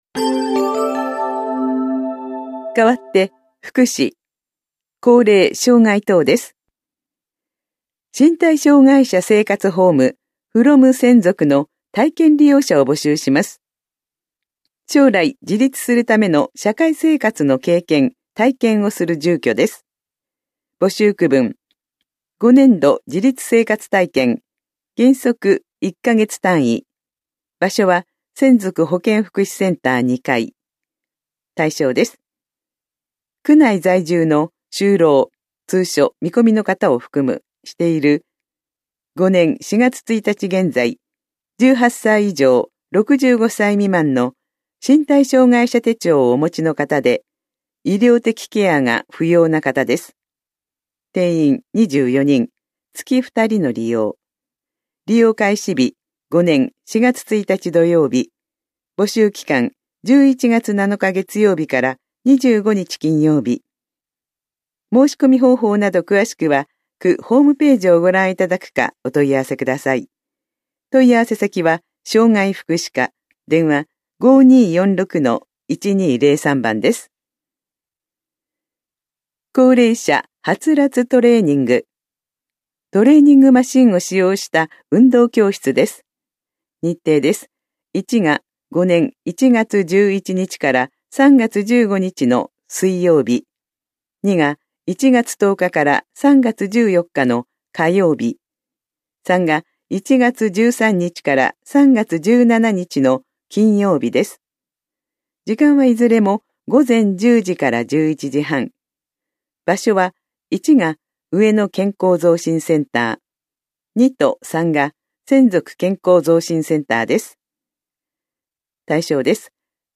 広報「たいとう」令和4年11月5日号の音声読み上げデータです。